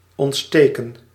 Ääntäminen
Tuntematon aksentti: IPA : /ˈkɪndl/